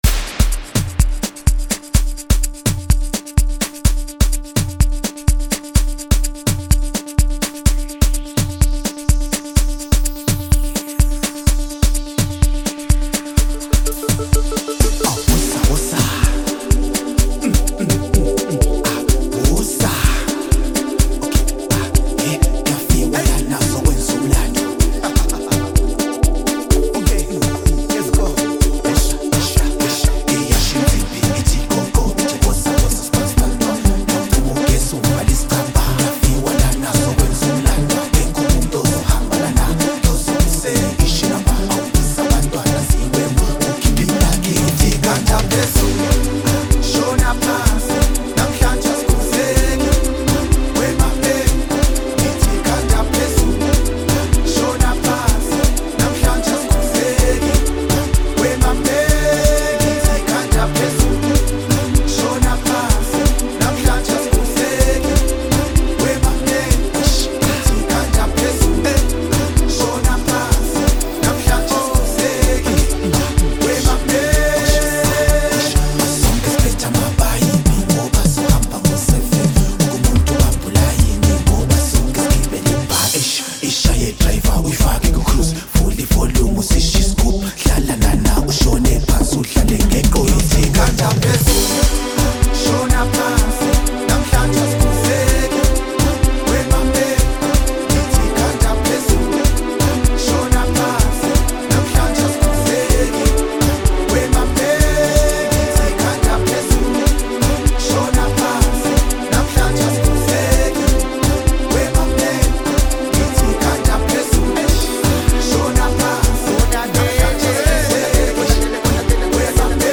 Afro-house and Gqom music